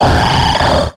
Grito de Claydol.ogg
Grito_de_Claydol.ogg.mp3